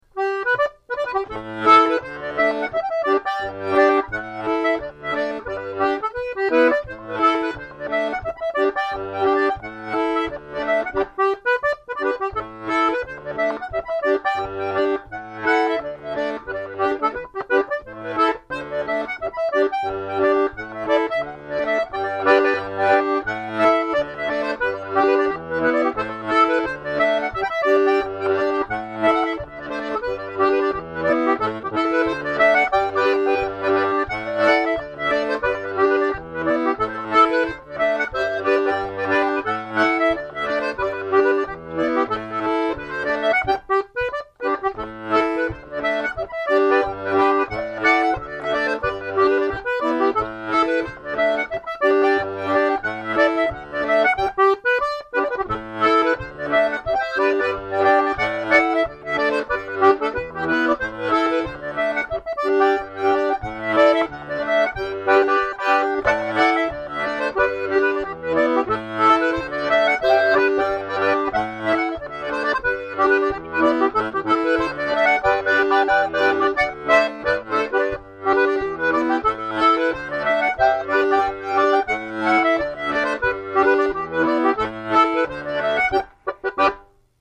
l'atelier d'accordéon diatonique
Pour l'apprendre utiliser les automatismes de la gamme de sol majeur (sol, la, si, do ré, mi, fa#) en poussé ou en tiré...